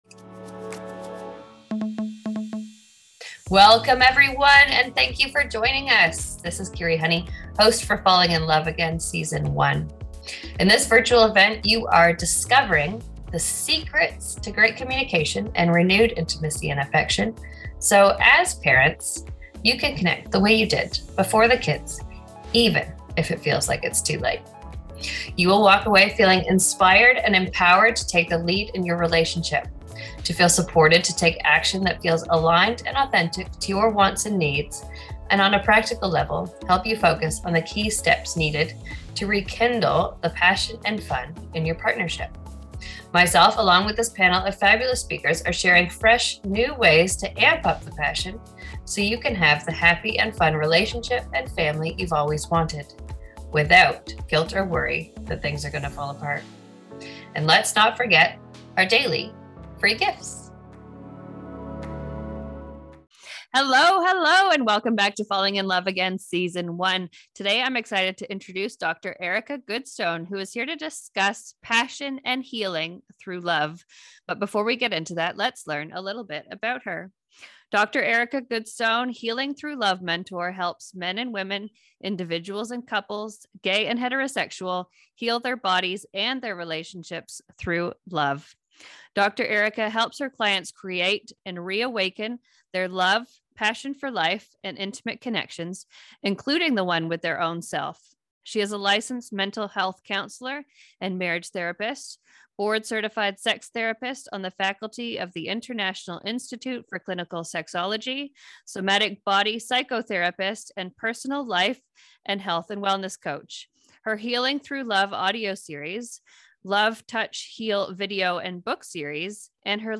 THE FALLING IN LOVE AGAIN SUMMIT AUDIO INTERVIEW